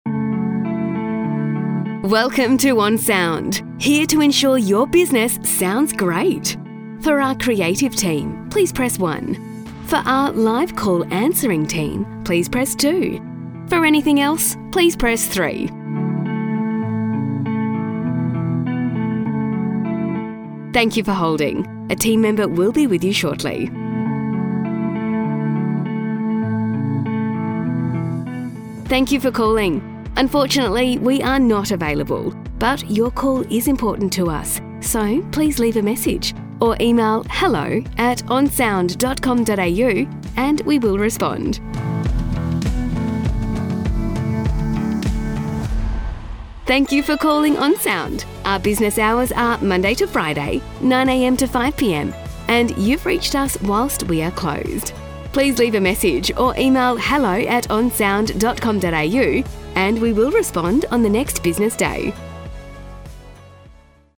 Example AI Voiceover Vs Professionally Recorded
Professional Recording